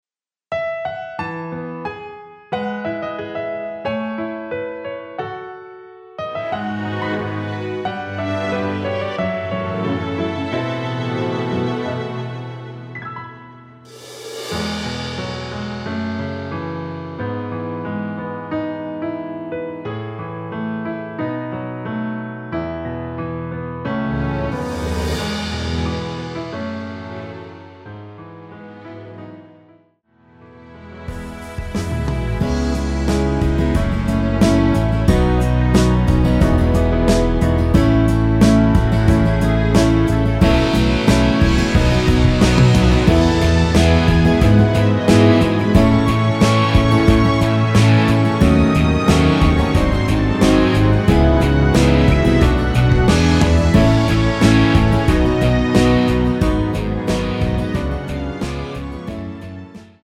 *(-1) 내린 MR 입니다.
◈ 곡명 옆 (-1)은 반음 내림, (+1)은 반음 올림 입니다.
앞부분30초, 뒷부분30초씩 편집해서 올려 드리고 있습니다.